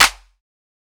Clap (oz).wav